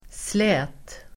Uttal: [slä:t]